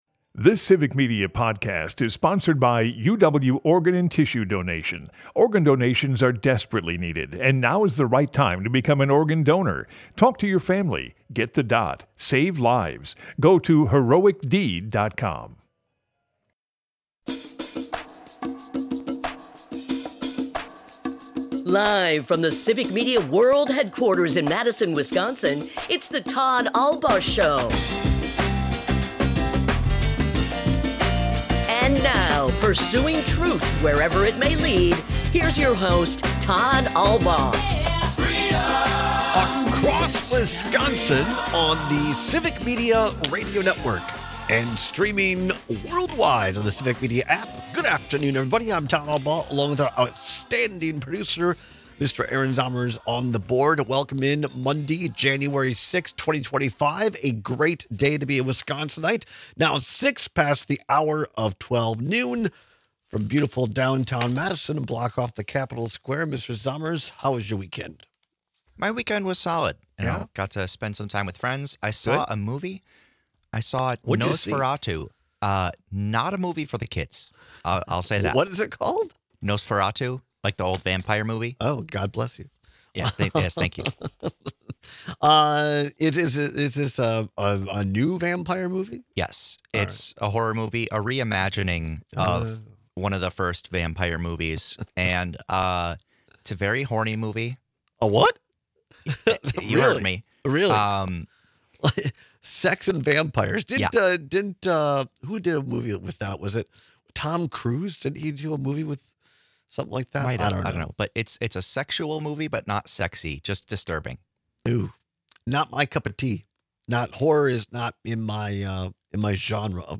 We also take a look back at our interview with former Capitol Police Officer Harry Dunn from June about what he saw that day.